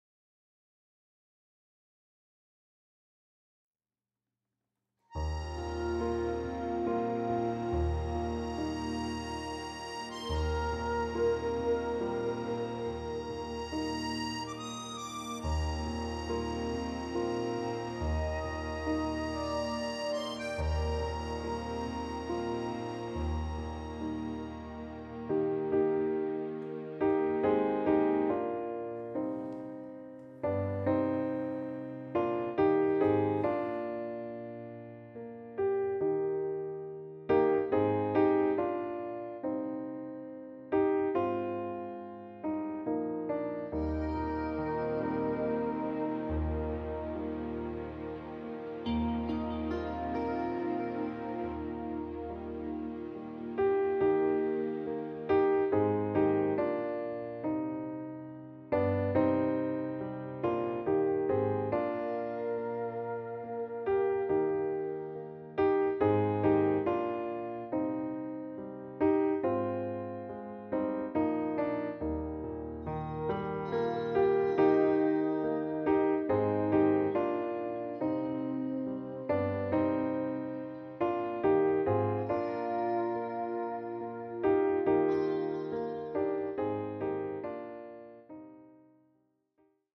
Das Playback-Album zur gleichnamigen Produktion.
Gemeindelied, Playback ohne Backings